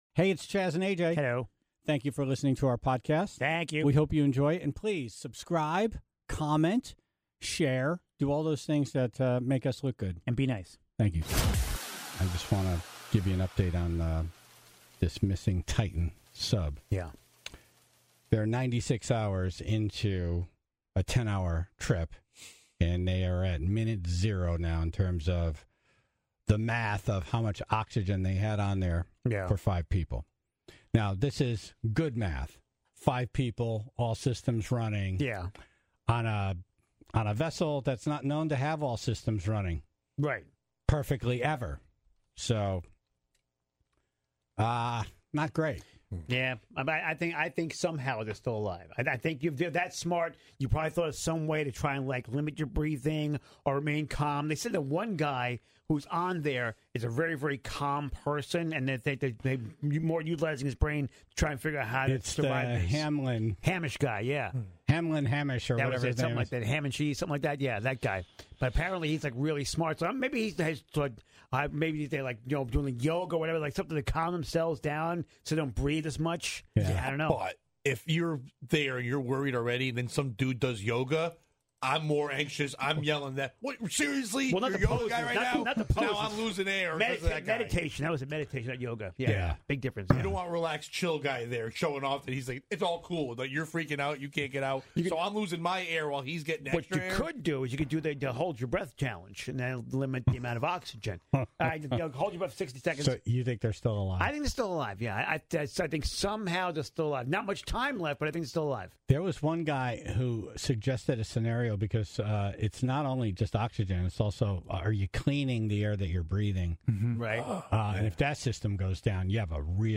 (31:11) Actor Harold Perrineau was on to address some recent news he made, regarding his experience on the show "Lost."